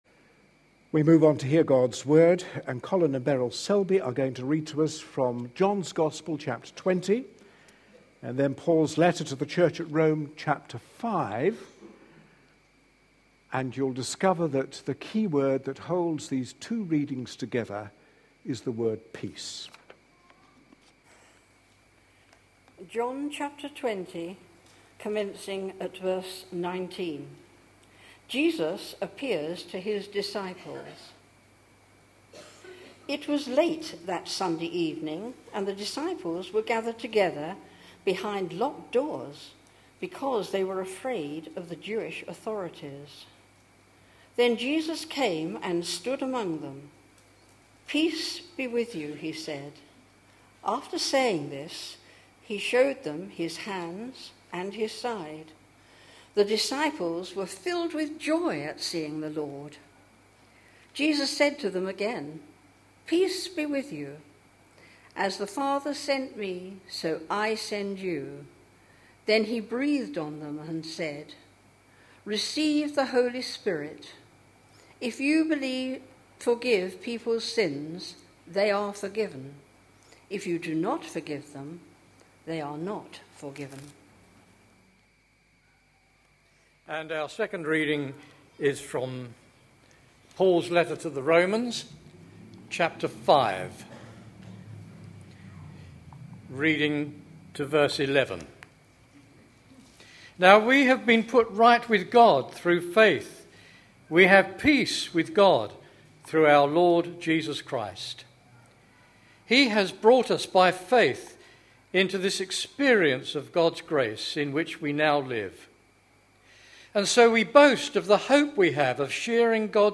A sermon preached on 14th April, 2013, as part of our Three things the risen Jesus said: series.